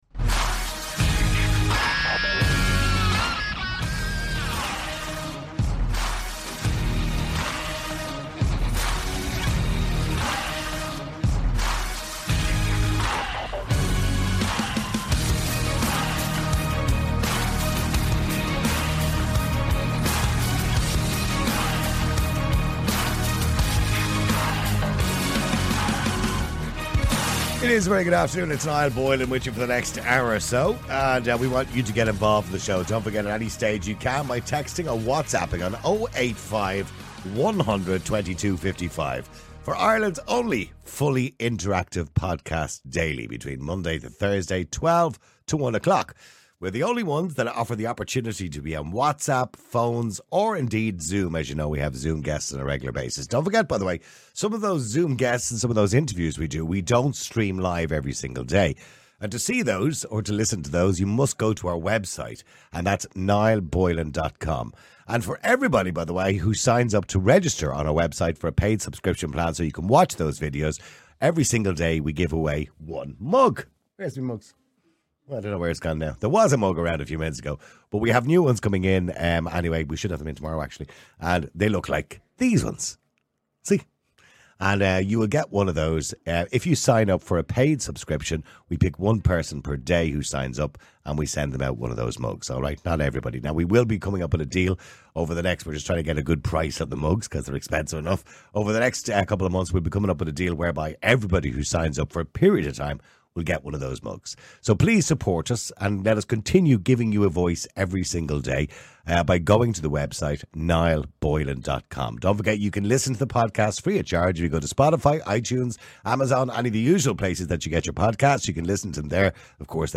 The responses vary widely. Some callers argue for an open-minded approach, emphasizing that love should know no boundaries.